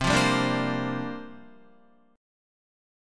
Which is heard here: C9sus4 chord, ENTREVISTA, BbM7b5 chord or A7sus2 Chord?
C9sus4 chord